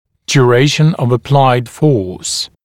[dju’reɪʃ(ə)n əv ə’plaɪd fɔːs][дйу’рэйш(э)н ов э’плайд фо:с]продолжительность приложенного усилия